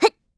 Hilda-Vox_Jump_kr.wav